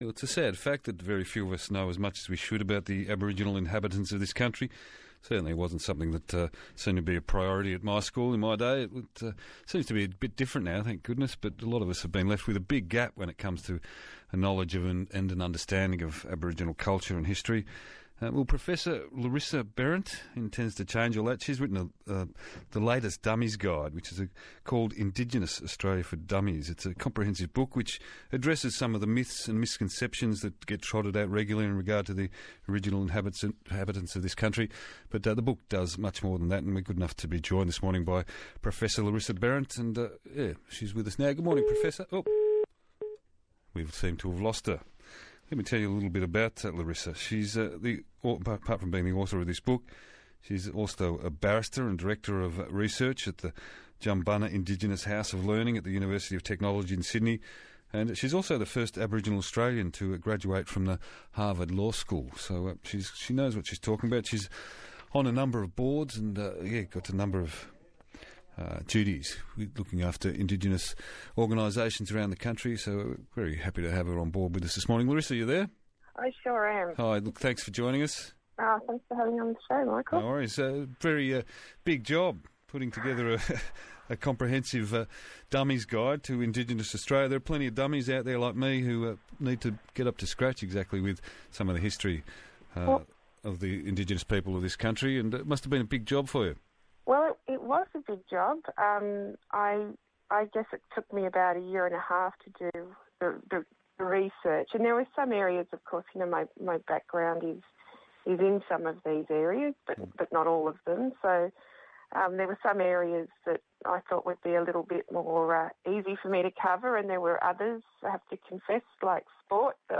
Not a real Book Review, but rather an interview with the author Larissa Behrendt about the book.